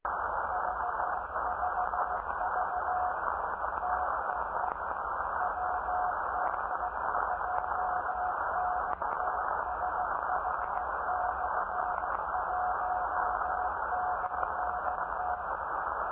Experimental Radio Station
Receiver is a Cushman CE-24
And his signal is nearly twice as loud now as it was when I recorded this.